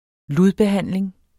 Udtale [ ˈluð- ]